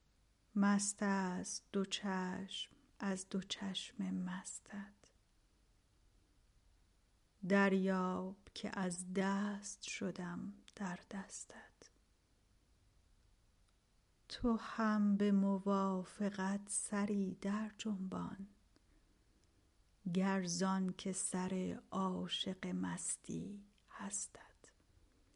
متن خوانش: